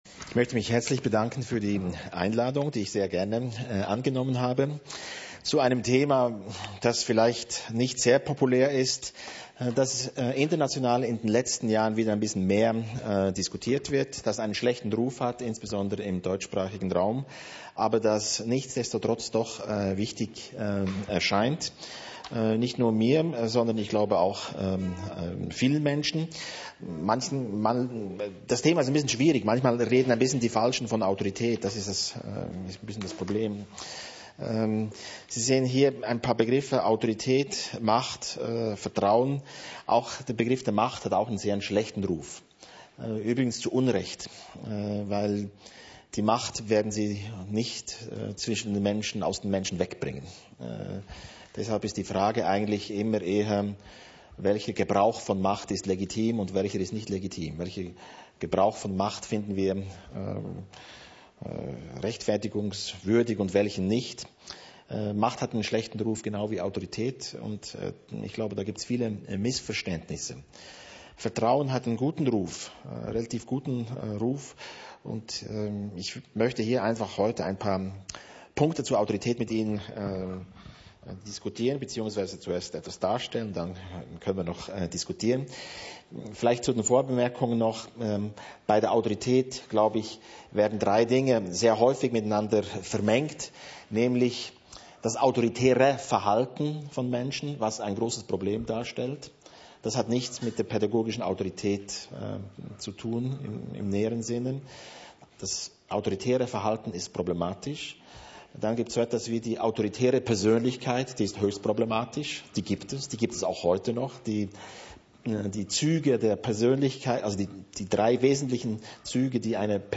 Vortrag Autorität, Teil 1